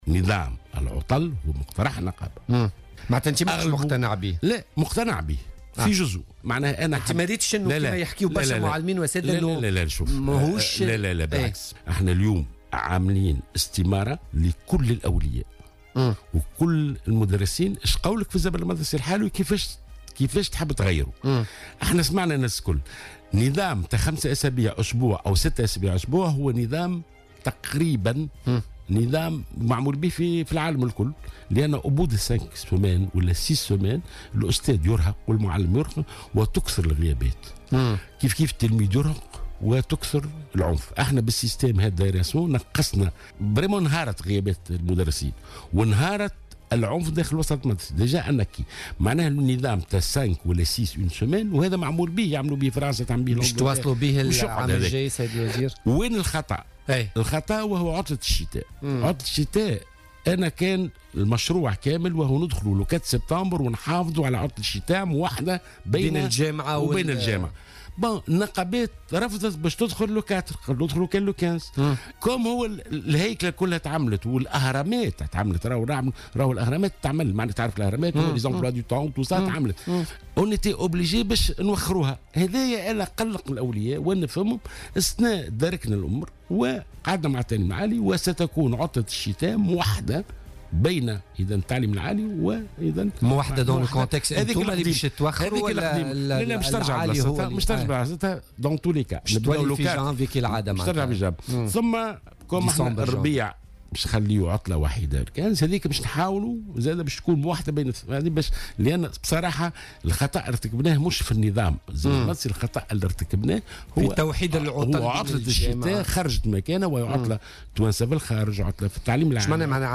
قال وزير التربية ناجي جلول لـ "الجوهرة اف أم" اليوم الاثنين إنه سيتم "الرجوع" إلى عطلتي الشتاء والربيع كما كانتا في السابق بدءا من السنة الدراسية المقبلة 2017-2018.